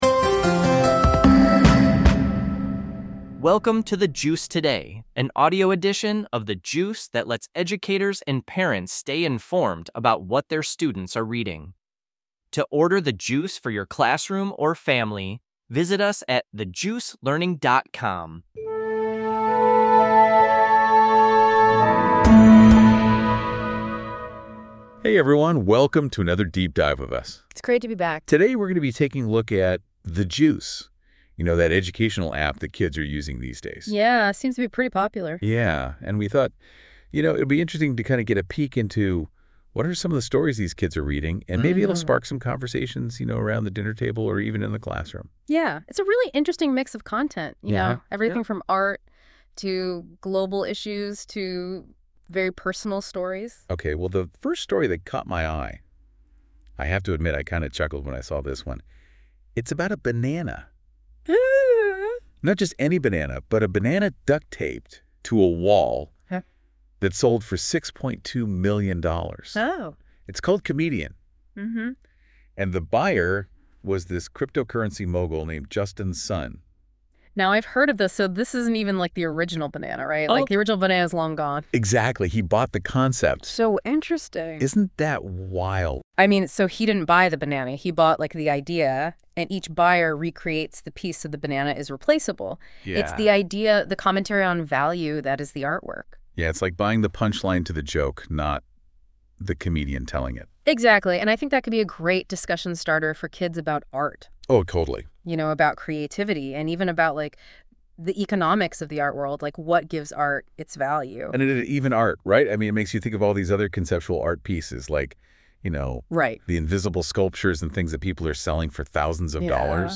$300 Billion Against Climate Change.Visit Us OnlineThe Juice Learning (for Educators) The Juice Today (for Parents)Production NotesThis podcast is produced by AI based on the content of a specific episode of The Juice.